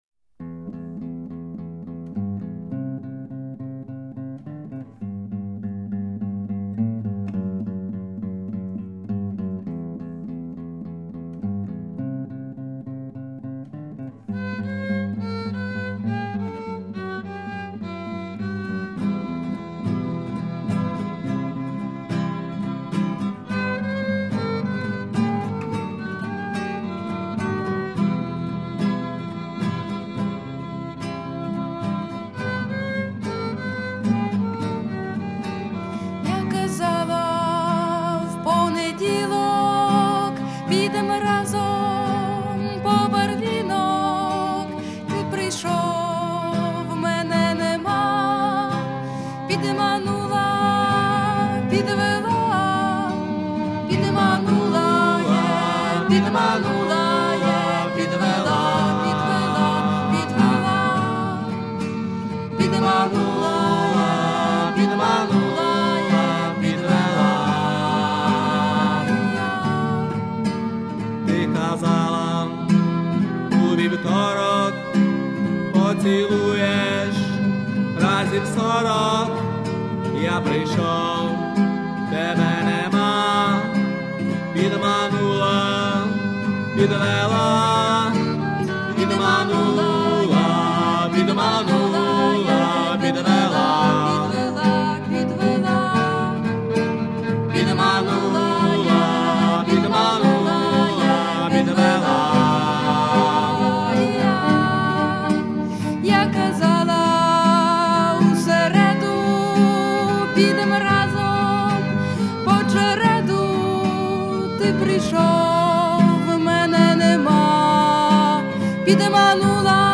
вокал
гітара
фон-вокал
скрипка